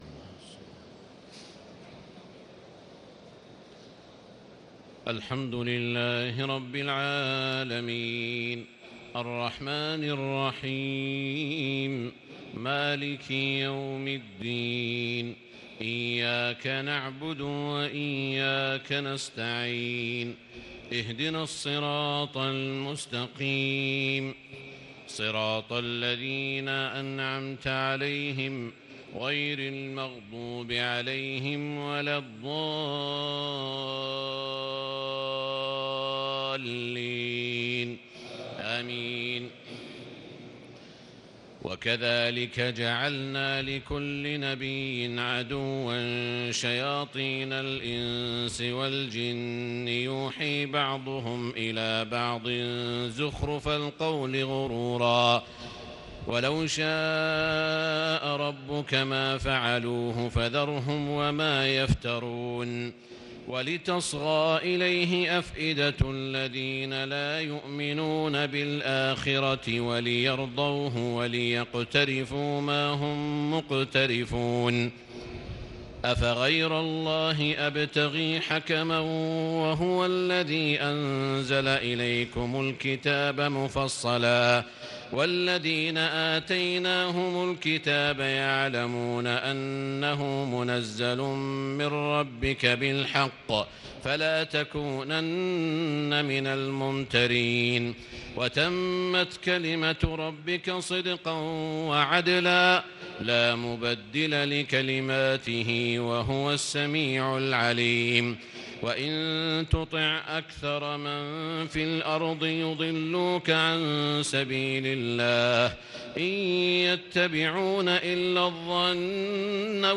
تهجد ليلة 28 رمضان 1438هـ من سورتي الأنعام (112-165) و الأعراف (1-30) Tahajjud 28 st night Ramadan 1438H from Surah Al-An’aam and Al-A’raf > تراويح الحرم المكي عام 1438 🕋 > التراويح - تلاوات الحرمين